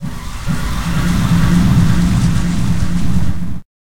breathe4.ogg